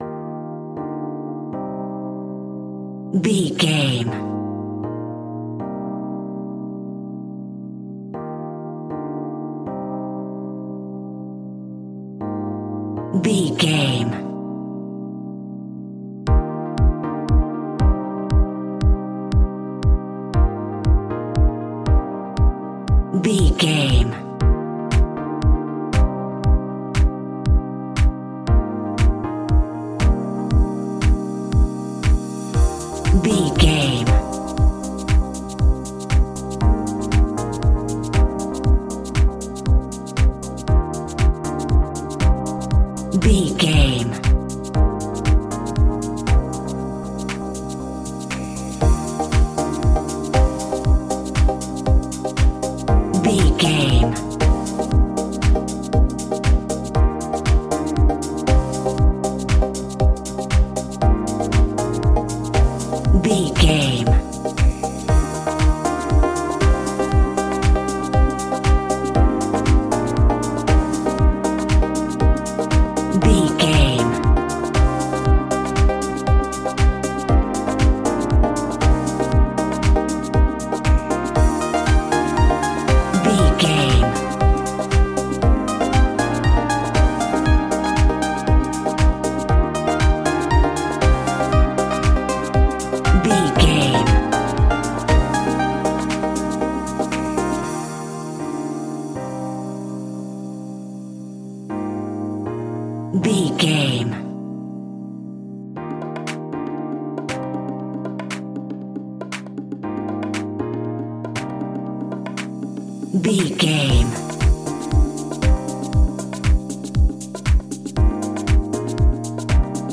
Ionian/Major
E♭
uplifting
energetic
bouncy
electric piano
drum machine
synthesiser
electro house
funky house
synth leads
synth bass